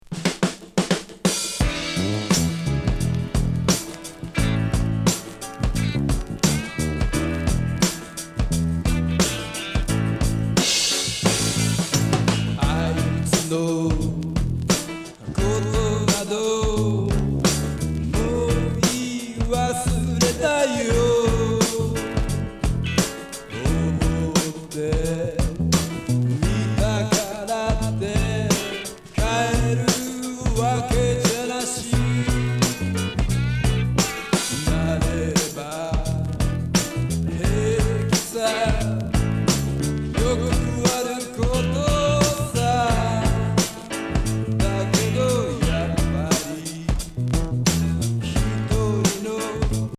国産スワンプ。ファンキー・フォーク